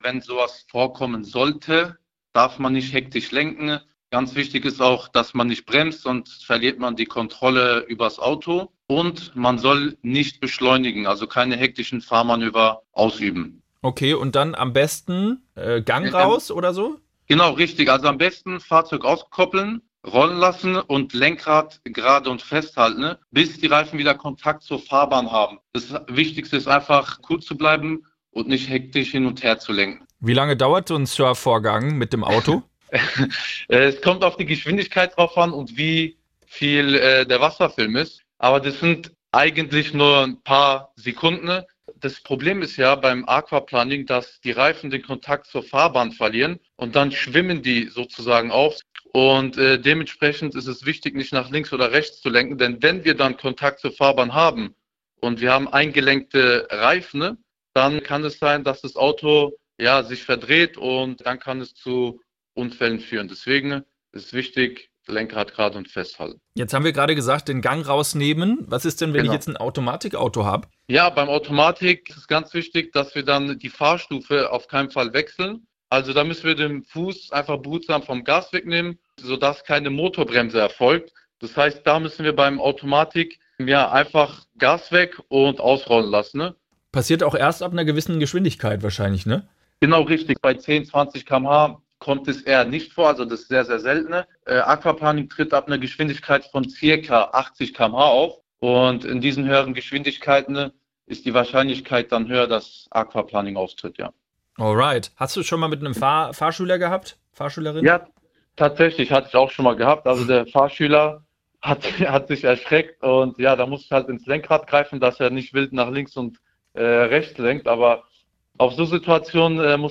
Das sagt der Fahrlehrer! SWR3 Interview: Aquaplaning – wie reagieren?